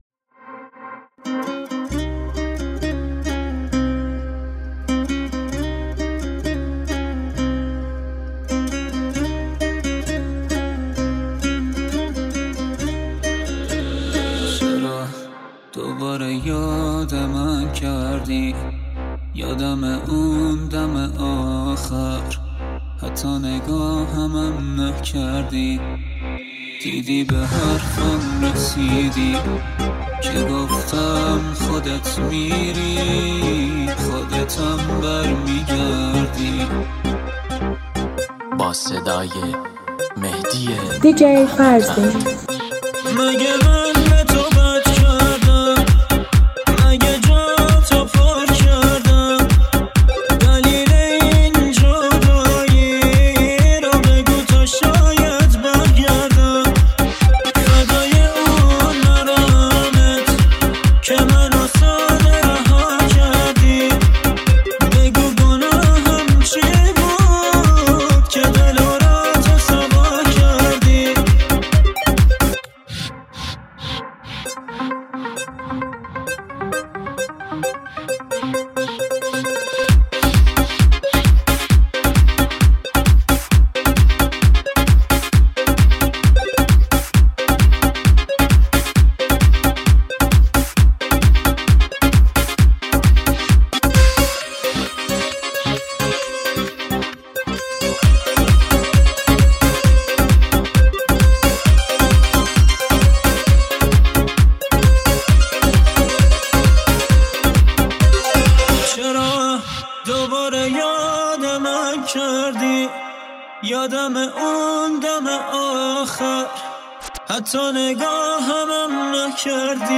آهنگ قدیمی
در سبک غمگین